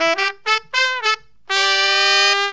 HORN RIFF 17.wav